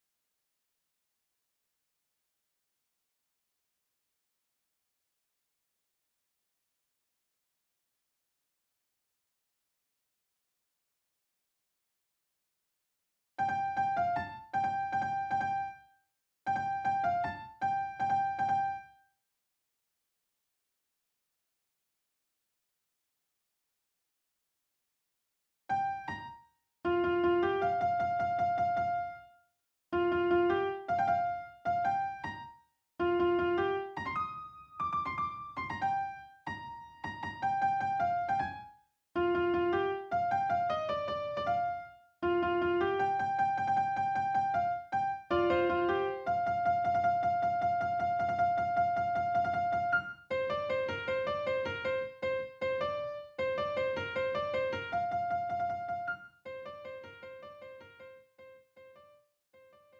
음정 여자키
장르 구분 Pro MR